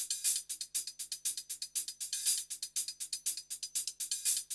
HIHAT LO11.wav